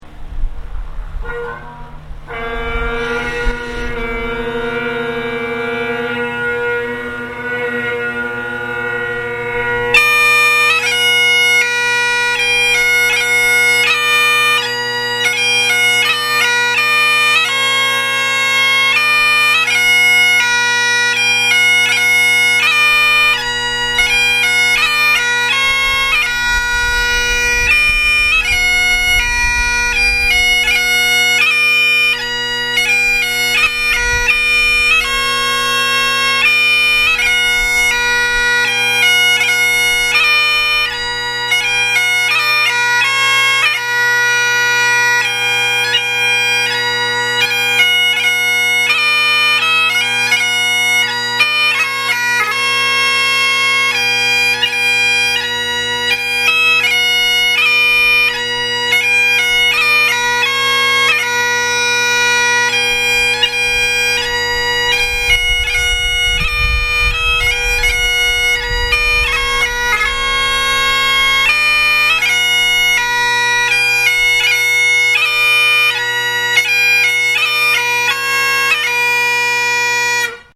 A pipe tune Captain Robert K. Runcie
Click here to hear the tune played by